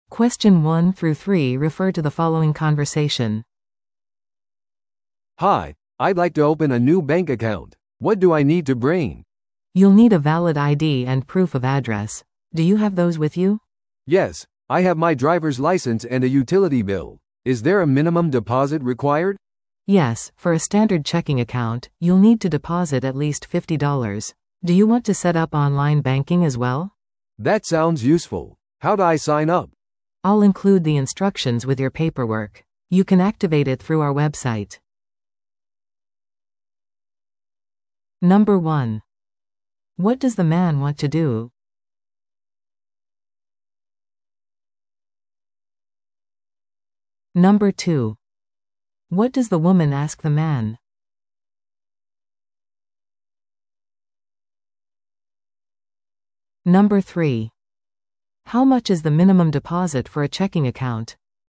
No.1. What does the man want to do?
No.2. What does the woman ask the man?